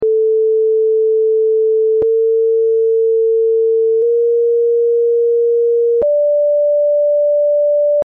tetracordo enarmonico
zar-enarmonico.mp3